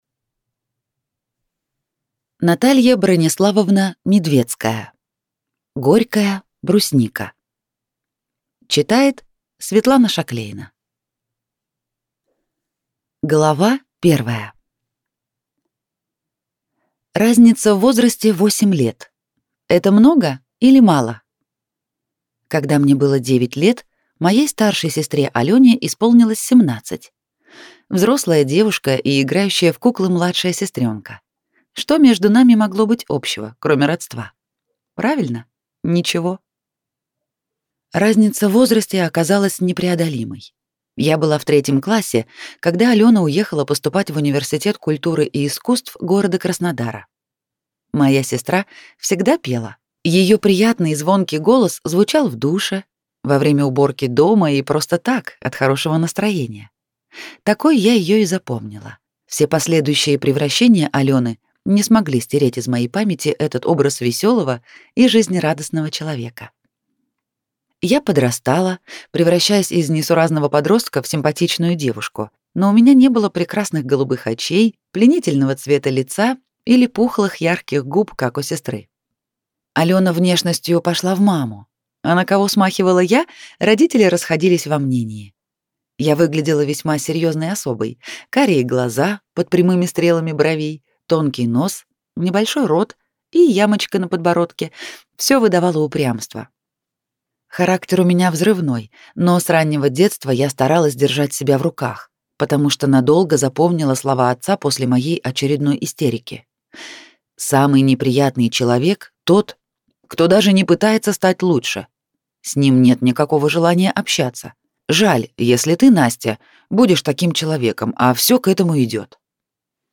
Аудиокнига Горькая брусника | Библиотека аудиокниг